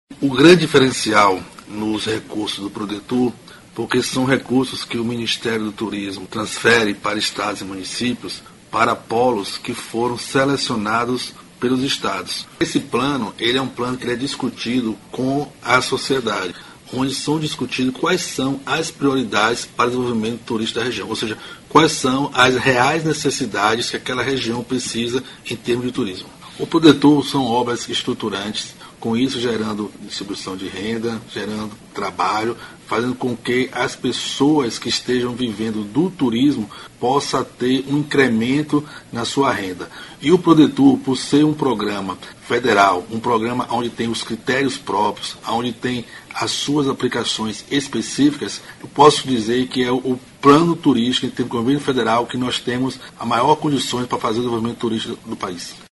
aqui e ouça declaração do secretário Carlos Sobral sobre a importância de investimentos do Programa Nacional de Desenvolvimento do Turismo (Prodetur).